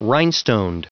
Prononciation du mot rhinestoned en anglais (fichier audio)
Prononciation du mot : rhinestoned